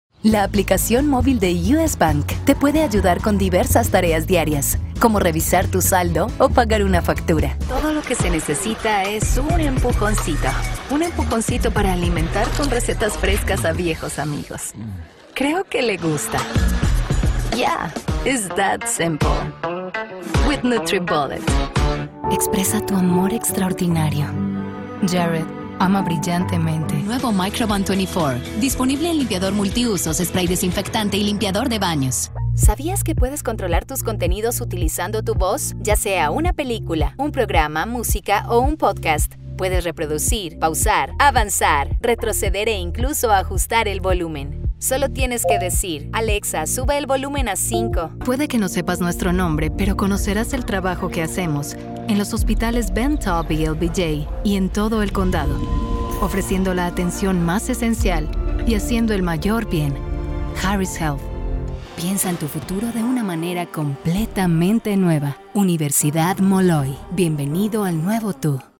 Espanhol (latino-americano)
Cabine de gravação profissional isolada